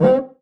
LCUICA LW.wav